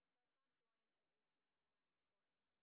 sp30_street_snr0.wav